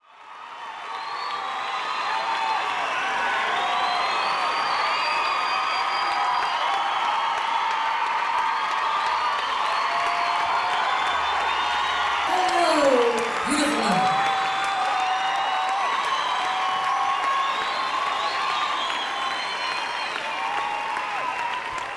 lifeblood: bootlegs: 1994-07-03: stratton mountain - stratton, vermont
(acoustic duo show)
01. crowd noise (0:22)